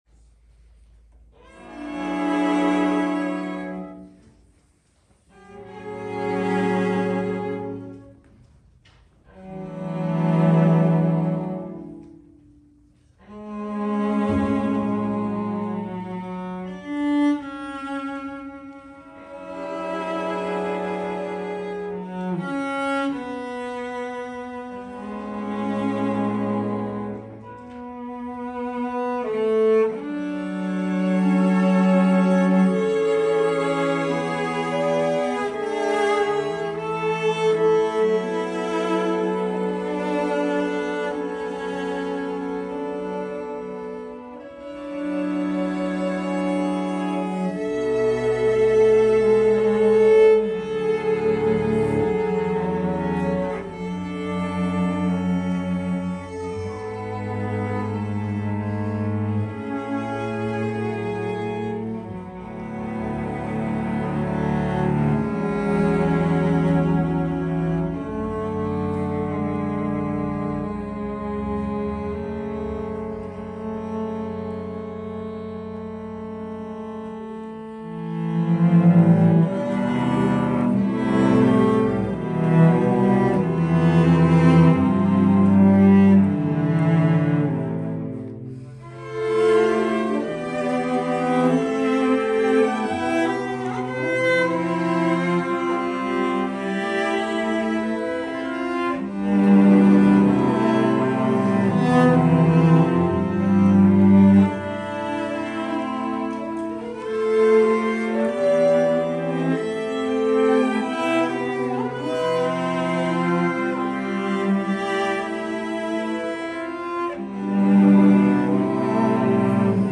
Through a YouTube video I found this very interesting cello quartet by Andrea Casarrubios.
The music is slow.
It has very close harmonies that require careful intonation.
Audio - recital quartet